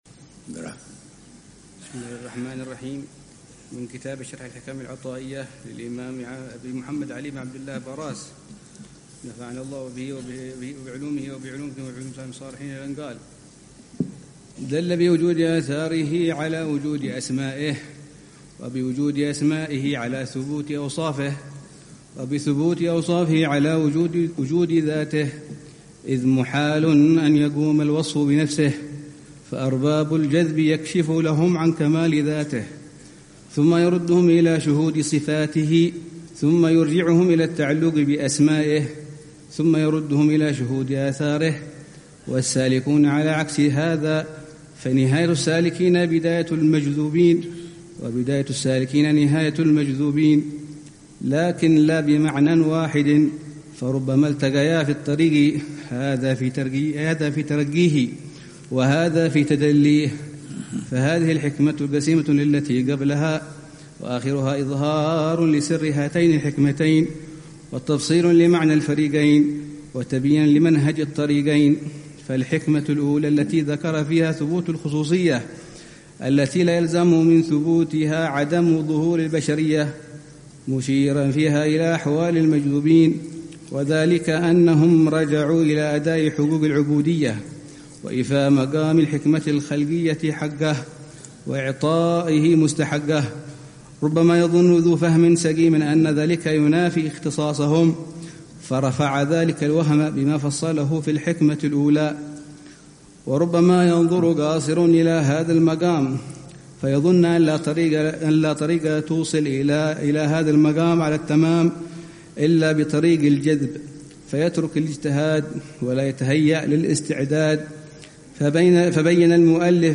شرح العلامة الحبيب عمر بن محمد بن حفيظ لكتاب شرح الحِكم العطائية للشيخ علي بن عبدالله با راس رحمه الله تعالى، ضمن فعاليات الدورة التعليمية الح